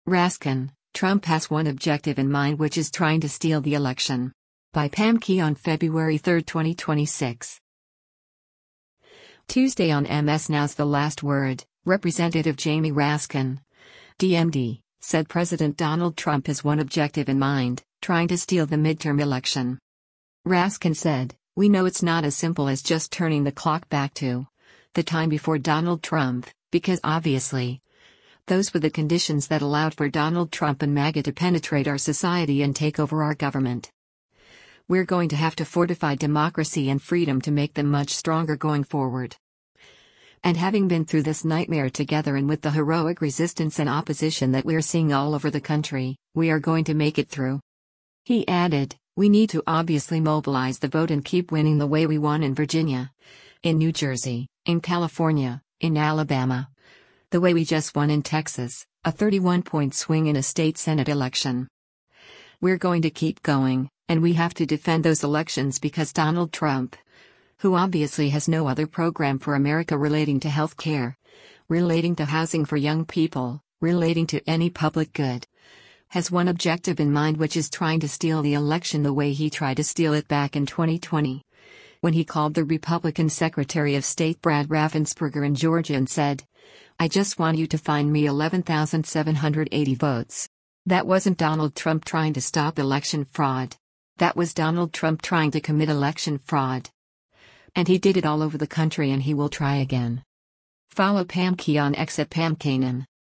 Tuesday on MS NOW’s “The Last Word,” Rep. Jamie Raskin (D-MD) said President Donald Trump has one objective in mind: “trying to steal” the midterm election.